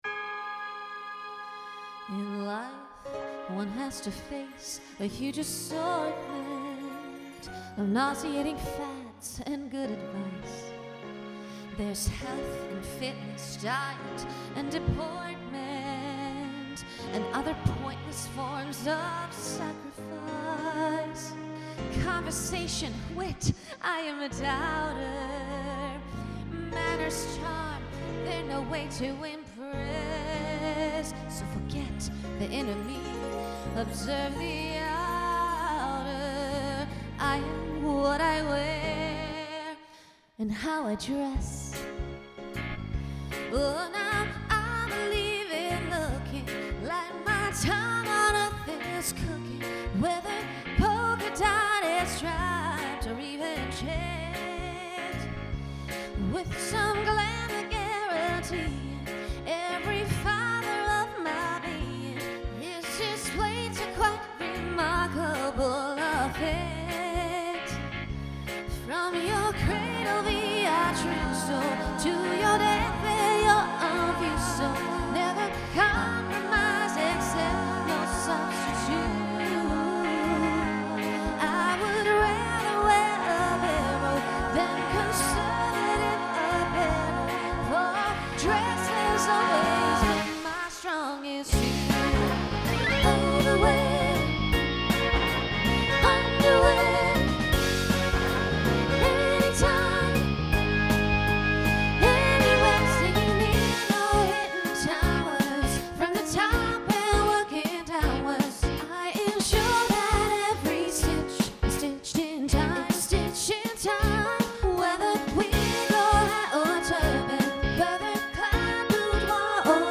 Voicing SSA Instrumental combo Genre Broadway/Film